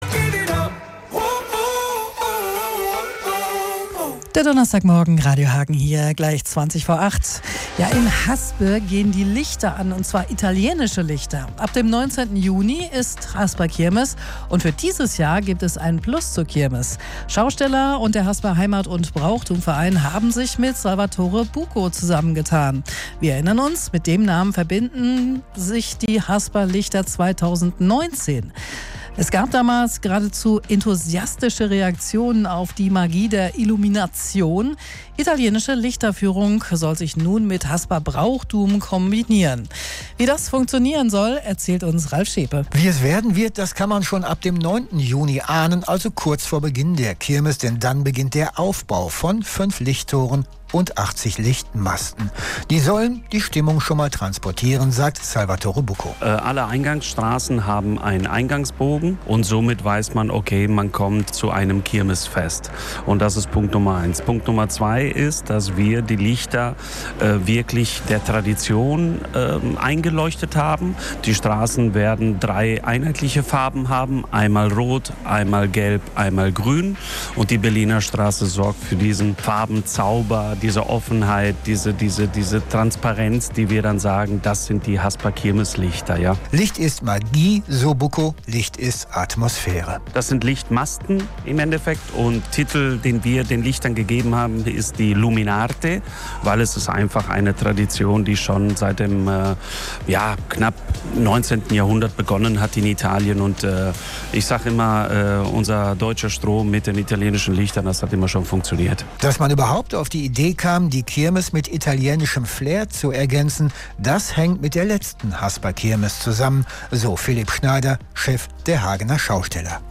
Dazu haben wir auch einen ausführlicheren Beitrag in unserem Radioprogramm gesendet.